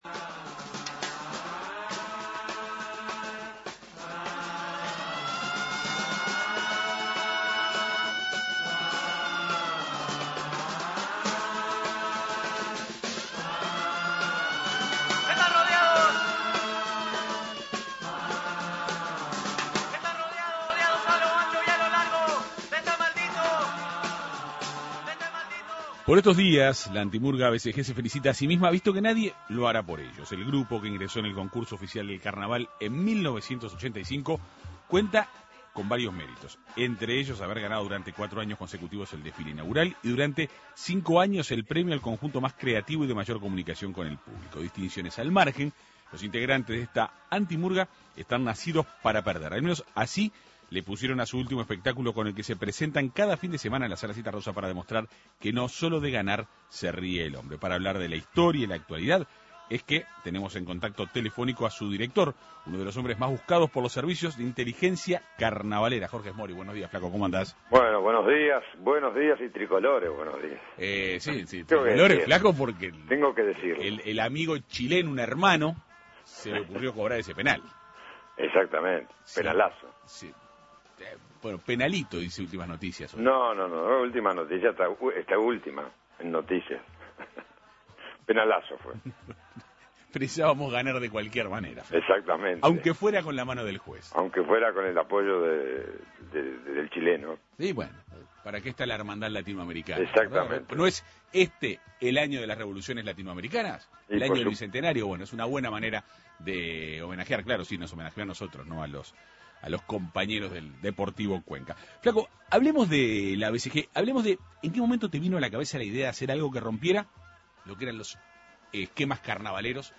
La Antimurga BCG, que ingresó al concurso oficial del Carnaval en 1985, presenta en la Sala Zitarrosa el espectáculo "Nacidos para perder". Para conocer más detalles sobre este grupo y su espectáculo, Jorge Esmoris, director de BCG, fue entrevistado por la Segunda Mañana de En Perspectiva.